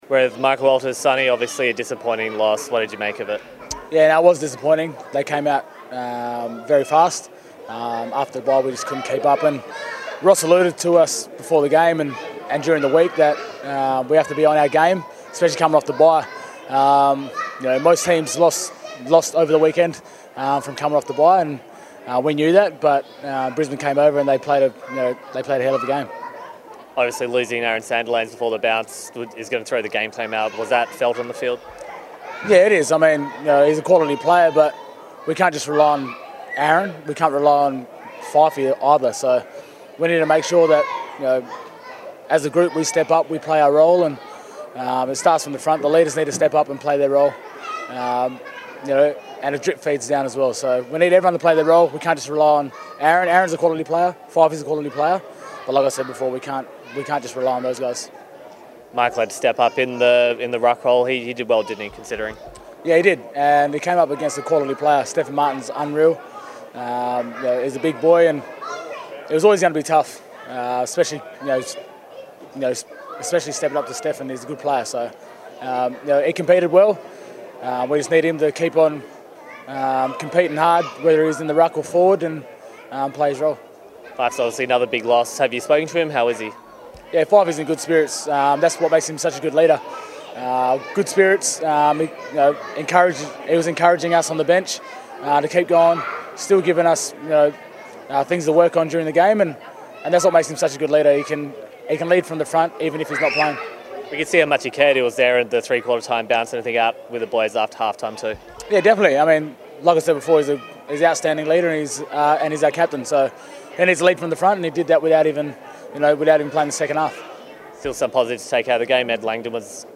Michael Walters spoke post-match following the loss against Brisbane